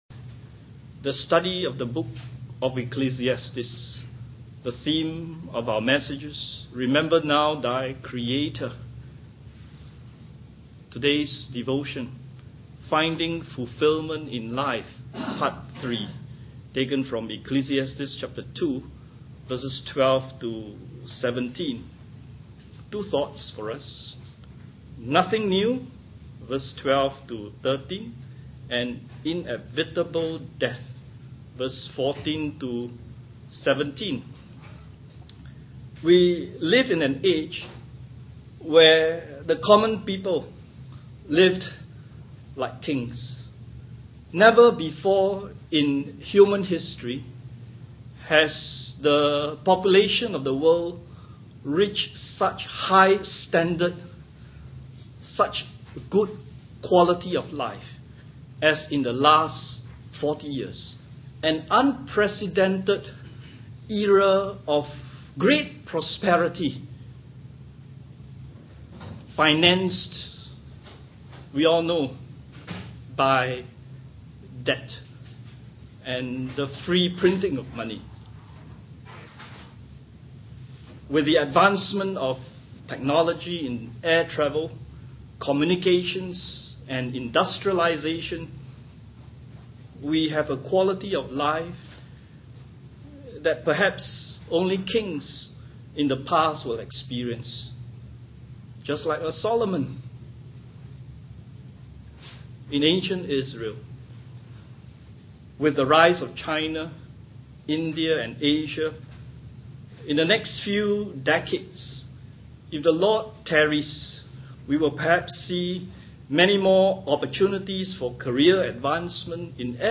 Hymns: 539 Beyond the Sunset; 538 The New Jerusalem; 544 When the Roll Is Called Up Yonder
Study of the Book of Ecclesiastes